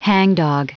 Prononciation du mot : hangdog
hangdog.wav